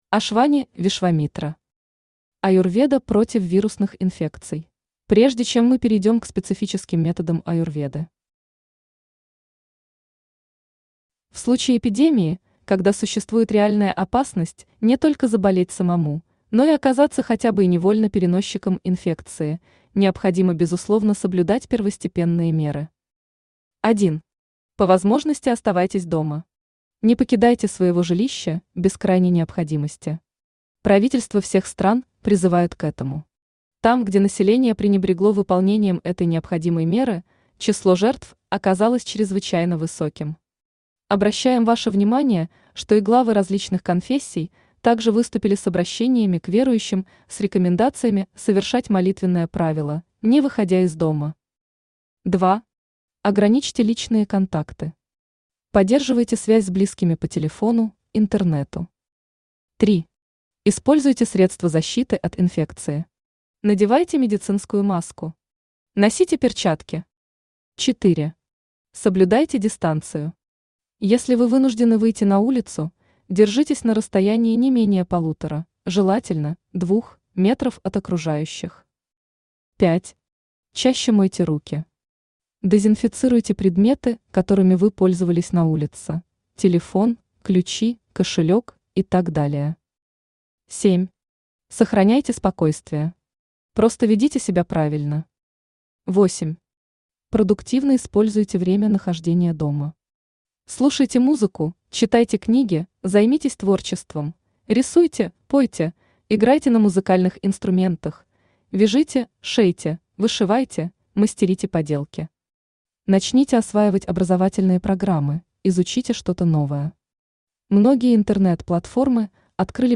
Аудиокнига Аюрведа против вирусных инфекций | Библиотека аудиокниг
Aудиокнига Аюрведа против вирусных инфекций Автор Ашвани Вишвамитра Читает аудиокнигу Авточтец ЛитРес.